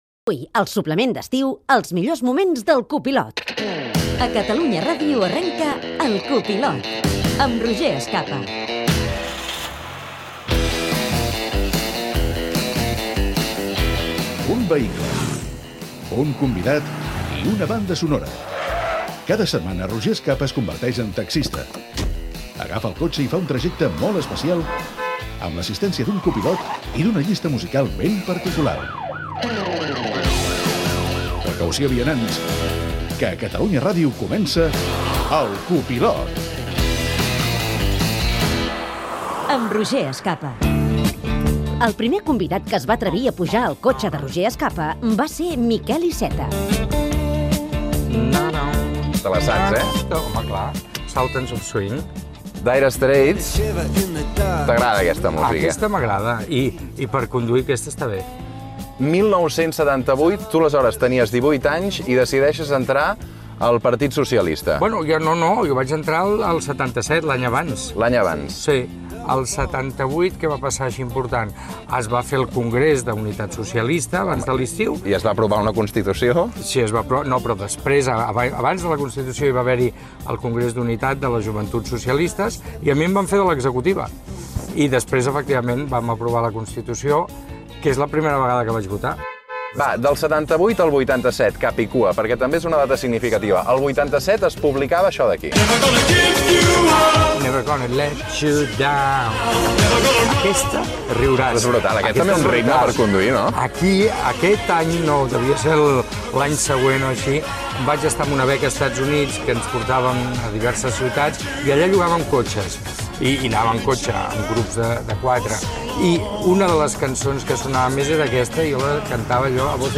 Careta de la secció "El copilot", entrevista al polític Miquel Iceta del Partit Socialista de Catalunya, anant en cotxe cap al Parlament de Catalunya, sobre els seus inicis a la política, records musicals, valoracions de Pedro Sánchez i altres polítics
Entreteniment